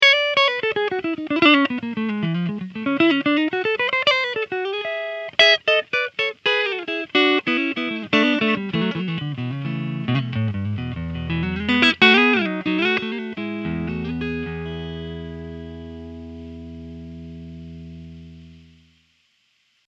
Clean riff 1